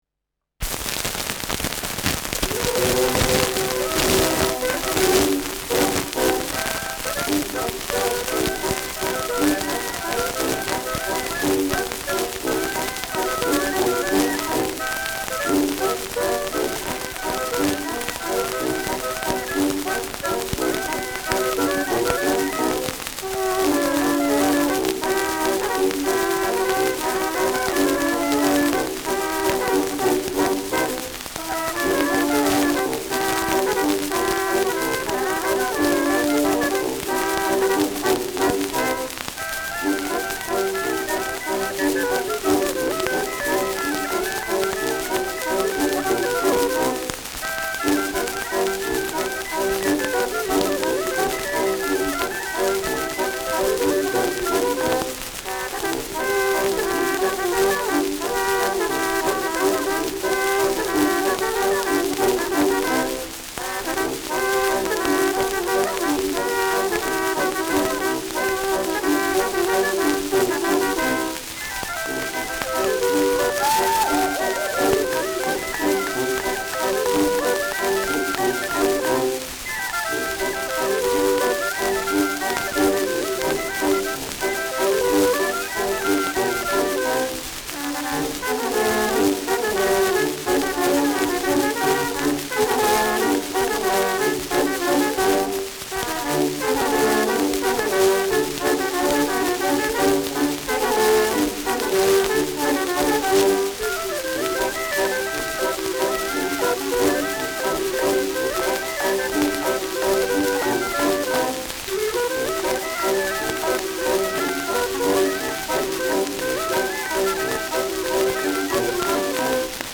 Schellackplatte
starkes Rauschen : gelegentlich dumpfes Knacken : leichtes Leiern : präsentes Knistern
Truderinger, Salzburg (Interpretation)
Mit Juchzer am Ende.